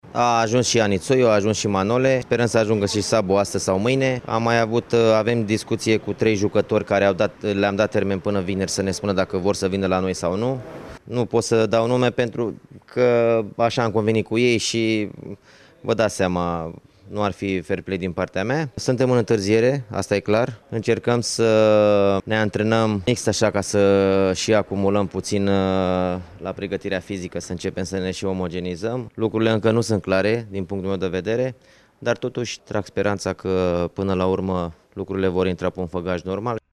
după antrenamentele de la mijlocul acestei săptămâni: